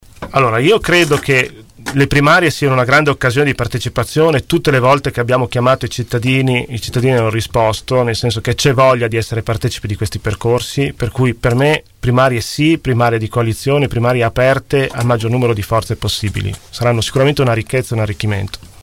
ospiti dei nostri studi.